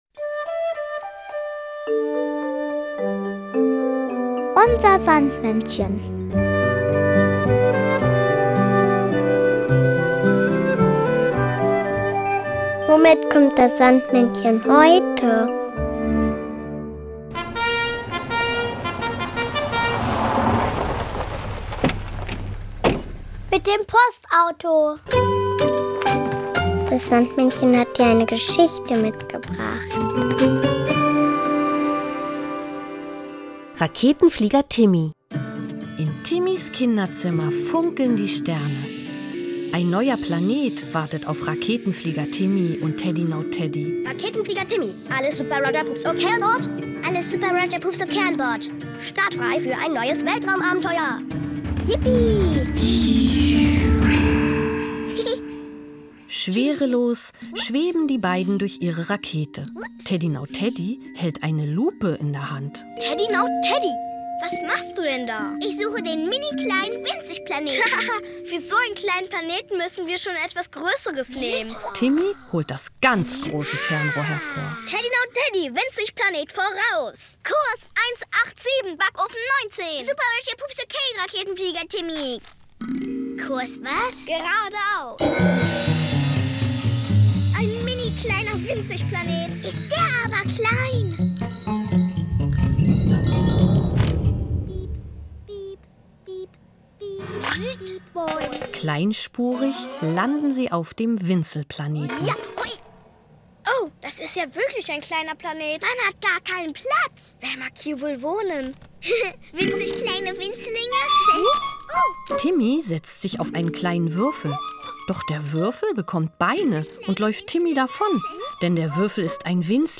Kinderlied "Wir werden immer größer" mit 3Berlin.